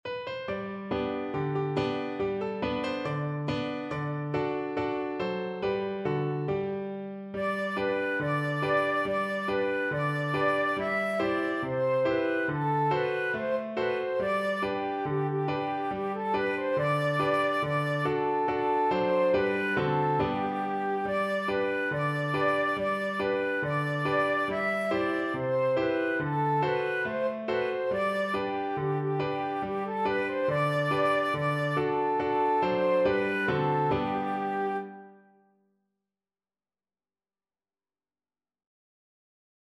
Happily =c.140
4/4 (View more 4/4 Music)
Classical (View more Classical Flute Music)